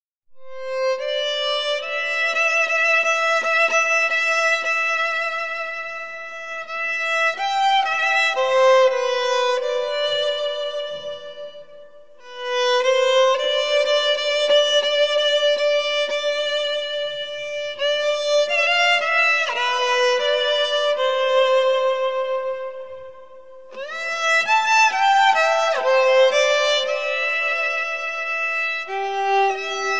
Polish Christmas Carols